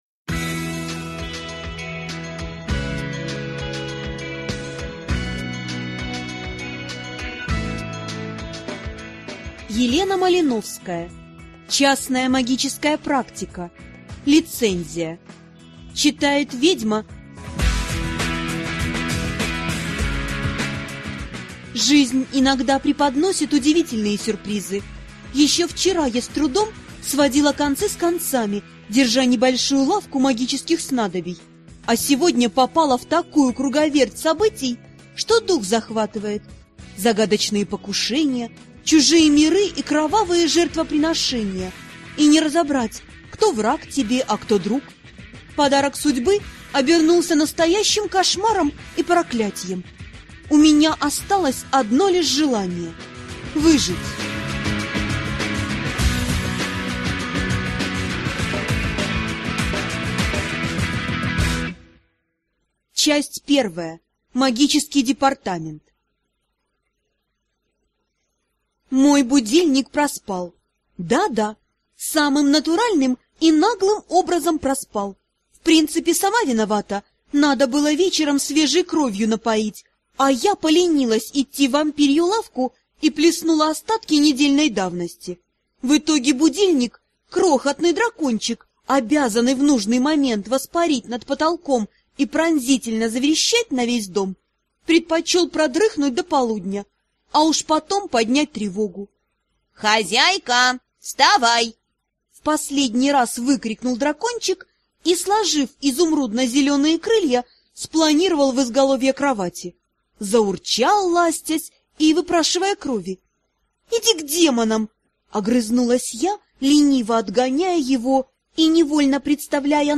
Аудиокнига Лицензия | Библиотека аудиокниг
Прослушать и бесплатно скачать фрагмент аудиокниги